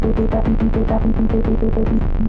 描述：循环的节奏来自Moog rogue和Phototheremin的即兴创作
Tag: EL ectroacoustic 样品 空间 老虎 循环 即兴 光特雷门 穆格